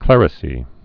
(klĕrĭ-sē)